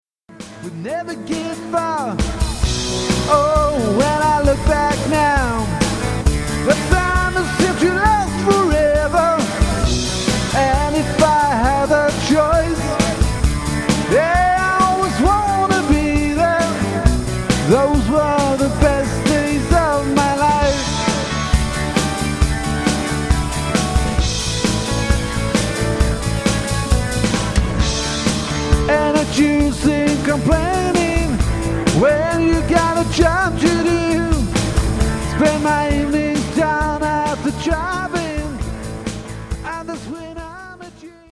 Vi har resurser att låta som ett betydligt större band.
• Coverband
• Hårdrock